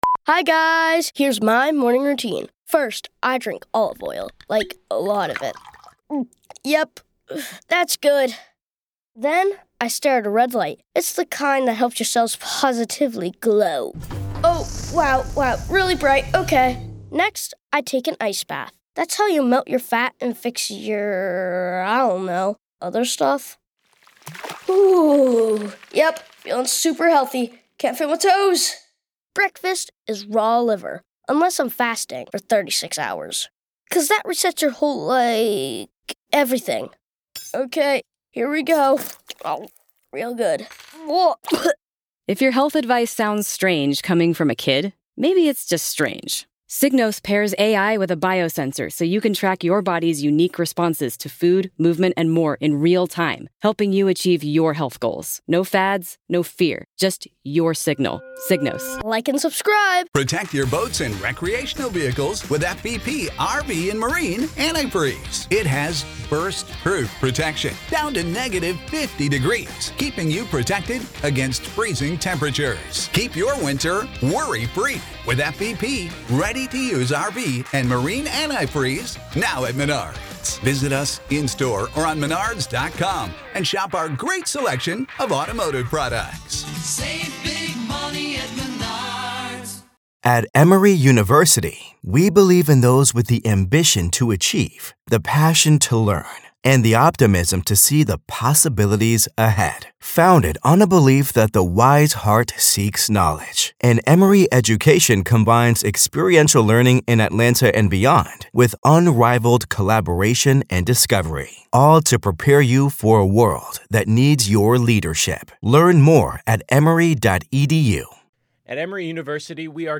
In this gripping segment, we analyze one of the most critical pieces of evidence in the Alex Murdaugh case: his first full interrogation with police, recorded from the back of a cruiser at the chaotic crime scene.
We play the raw footage, allowing you to see and hear for yourself how Murdaugh describes the horrific discovery.